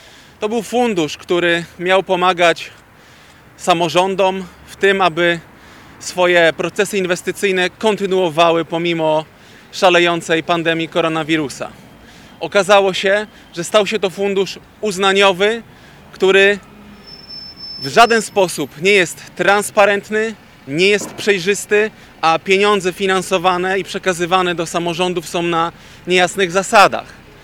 SZCZ-Marchewka-Konferencja.mp3